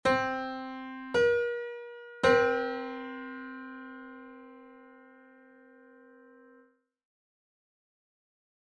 Piano_13_15edo.mp3